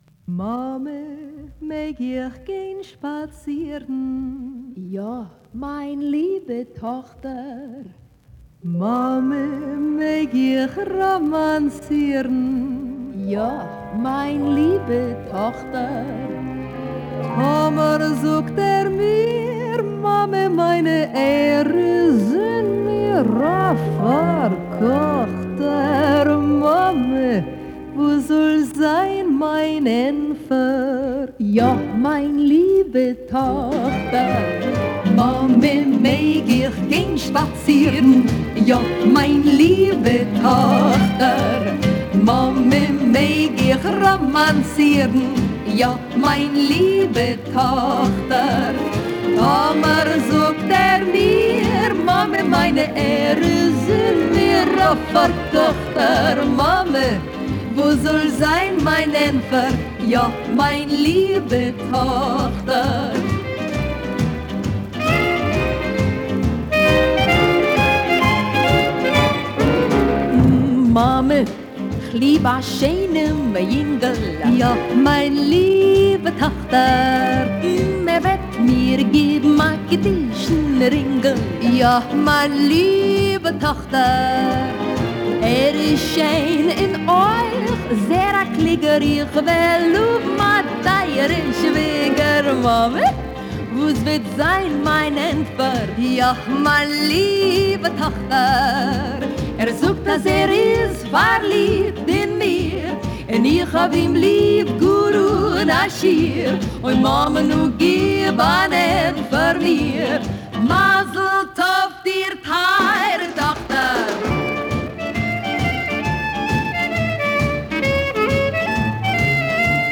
вокальном дуэте